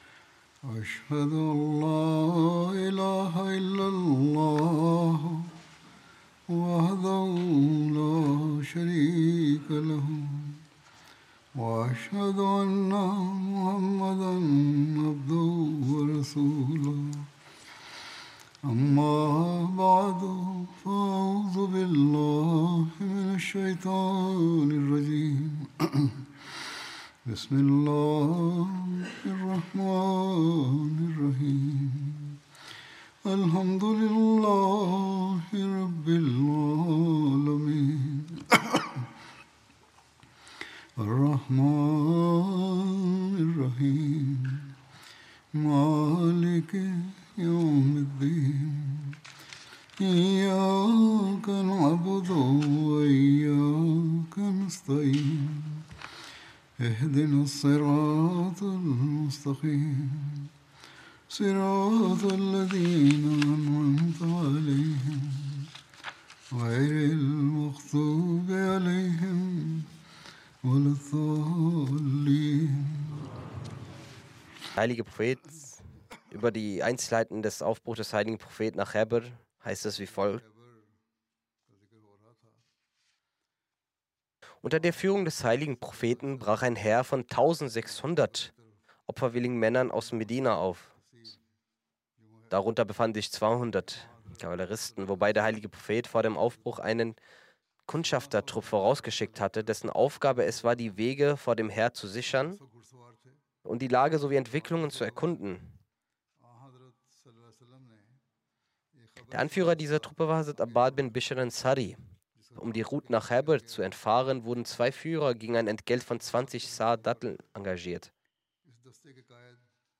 German Translation of Friday Sermon delivered by Khalifatul Masih